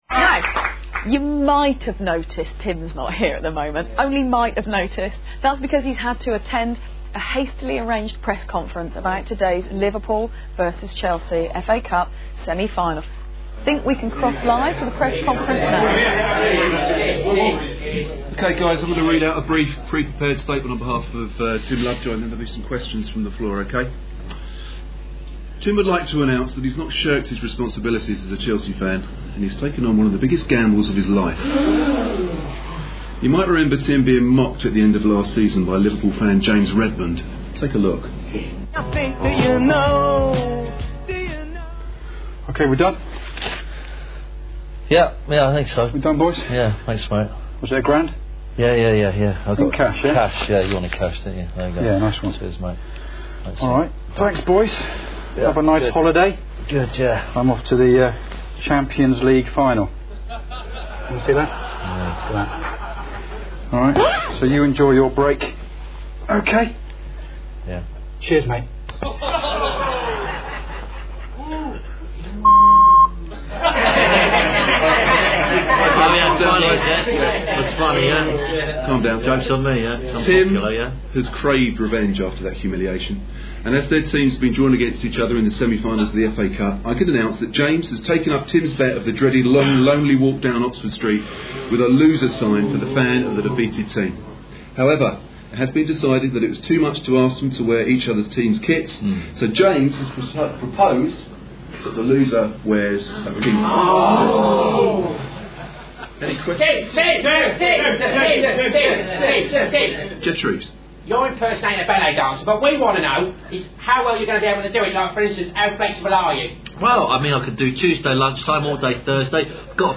Press Conference - F.A Cup semi-final bet (461kb)